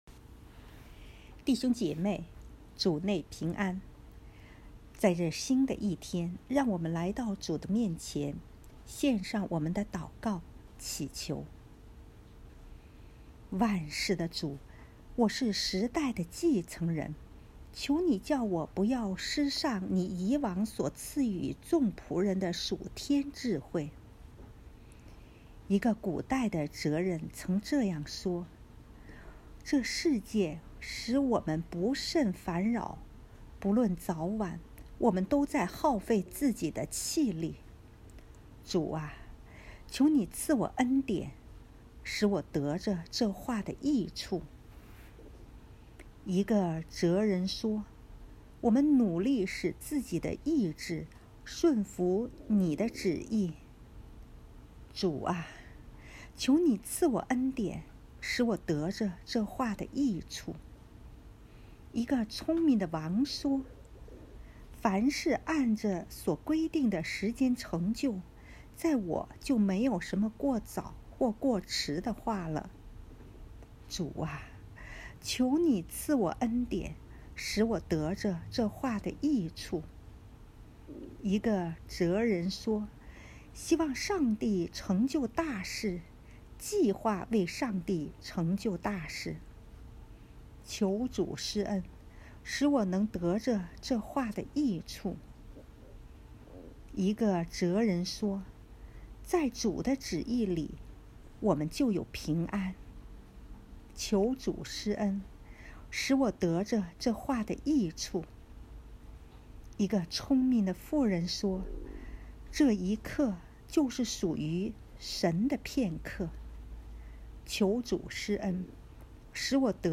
第二十五日晨祷